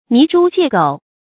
泥豬疥狗 注音： ㄋㄧˊ ㄓㄨ ㄐㄧㄝ ˋ ㄍㄡˇ 讀音讀法： 意思解釋： 比喻卑賤或粗鄙的人。